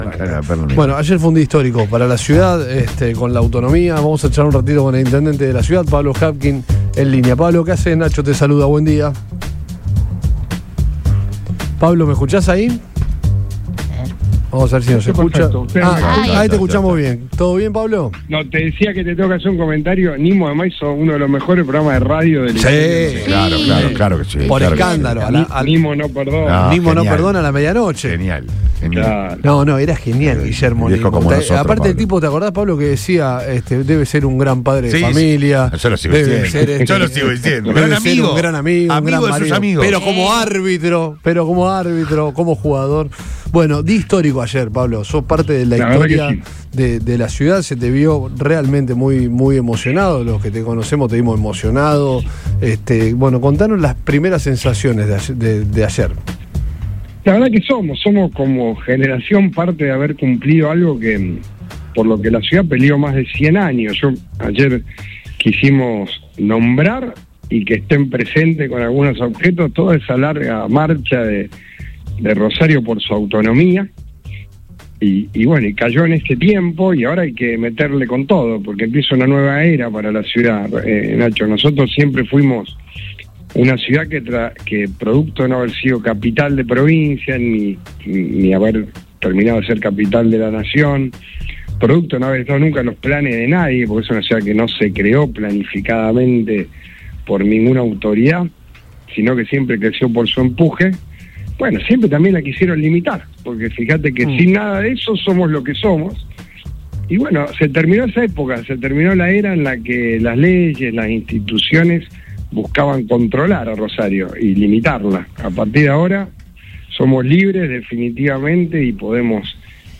El intendente de Rosario, Pablo Javkin, habló en Todo Pasa de Radio Boing tras la histórica declaración de autonomía de la ciudad. Visiblemente emocionado, el mandatario destacó que este logro pone fin a una larga historia de limitaciones impuestas desde otros niveles de gobierno.